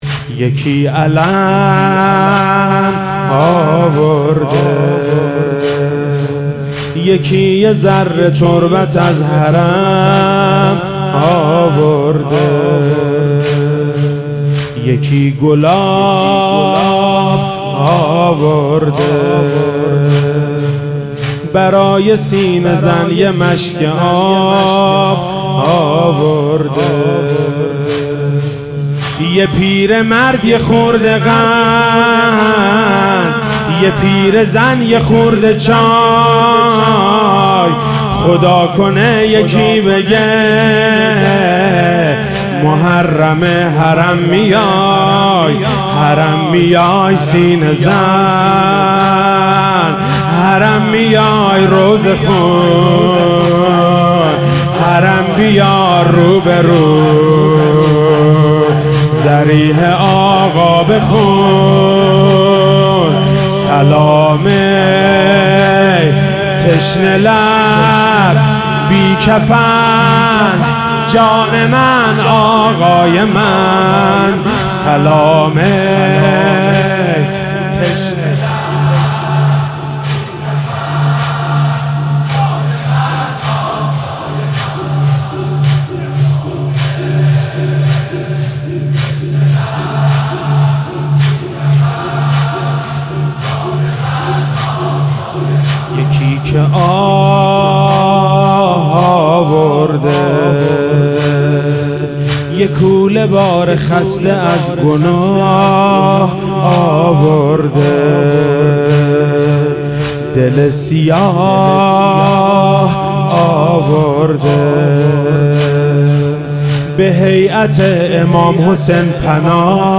مداحی زیبا و شنیدنی شب اول محرم 1396
هیئت انصار الحجه مشهد مقدس ----------------------------------------------